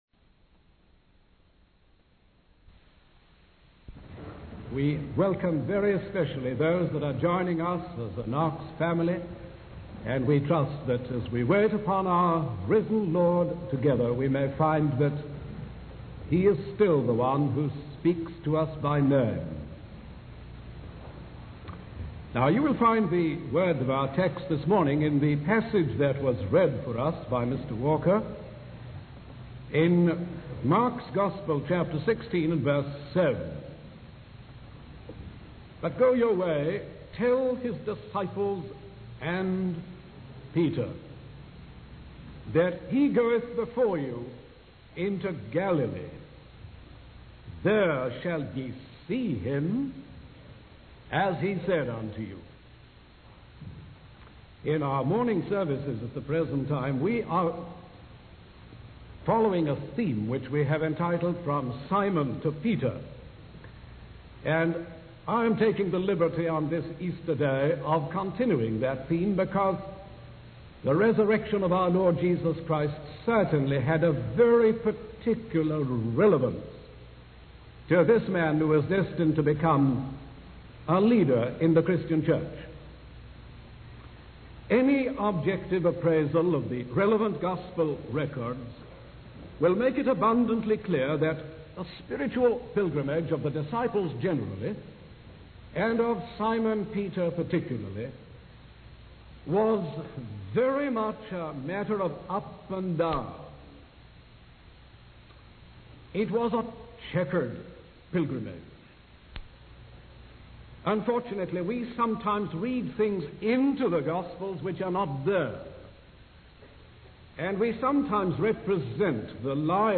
In this sermon, the preacher emphasizes the perseverance of the Lord in the lives of His followers. He acknowledges that the disciples, including Simon Peter, had a checkered spiritual journey with ups and downs.